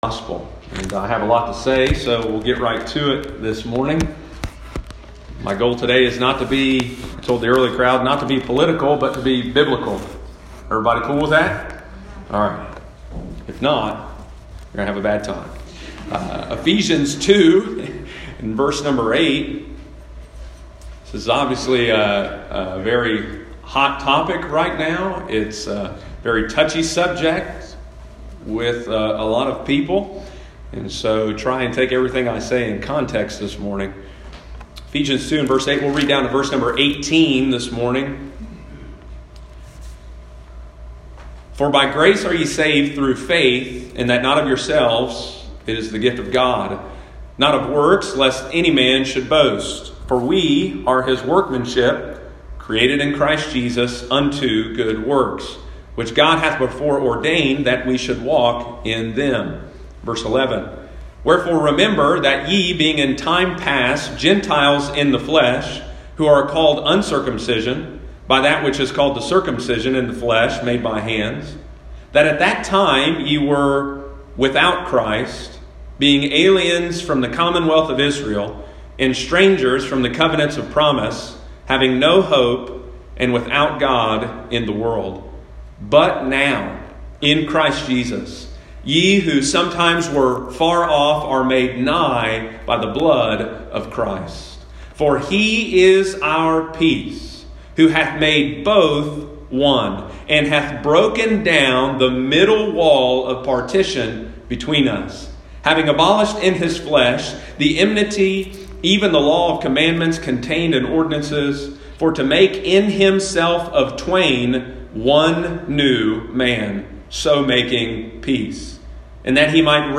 Sunday morning, June 7, 2020.